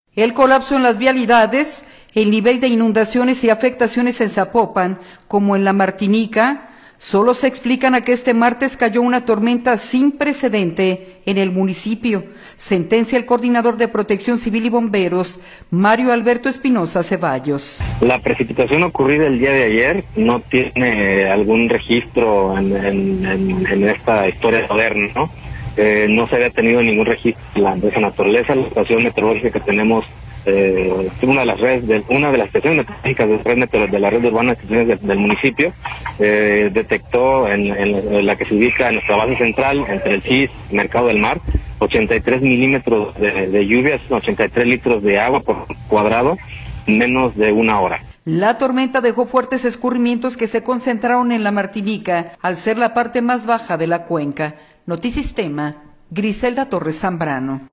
El colapso en las vialidades, el nivel de inundaciones y afectaciones en Zapopan como en La Martinica, solo se explican a que este martes cayó una tormenta sin precedente en el municipio, sentencia el coordinador de Protección Civil y Bomberos, Mario Alberto Espinosa Ceballos.